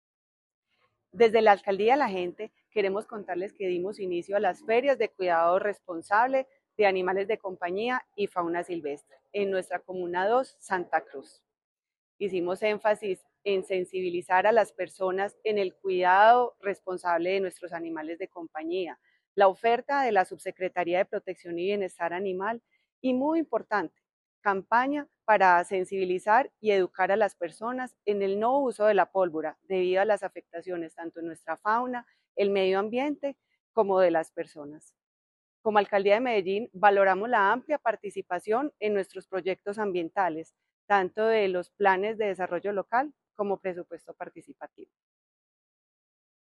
Declaraciones-subsecretaria-de-Proteccion-y-Bienestar-Animal-Elizabeth-Coral-Duque.mp3